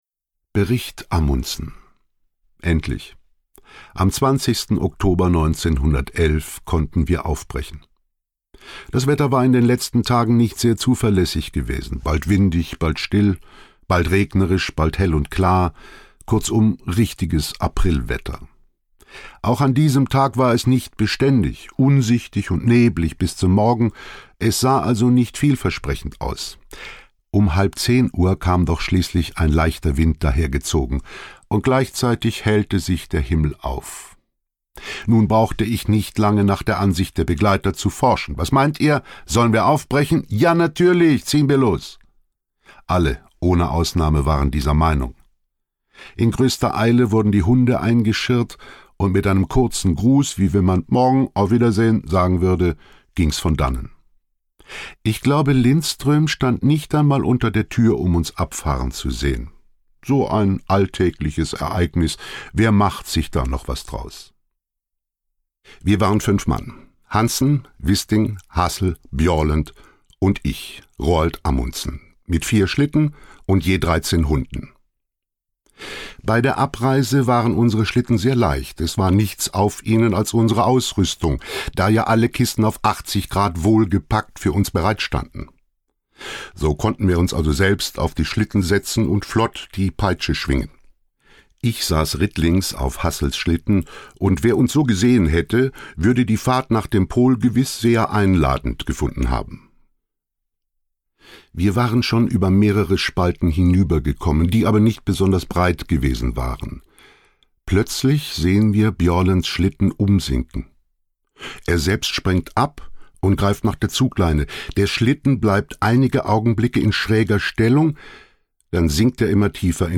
In dieser spannenden Lesung ausgewählter Passagen aus Scotts Tagebuch und Amundsens Reisebericht werden die Geschehnisse für den Hörer noch einmal lebendig.